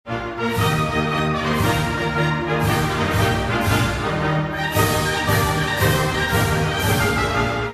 The cheerful tune you're
hearing is this composer's overture to "H.M.S. Pinafore"